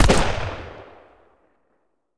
Revolver_Shoot 02.wav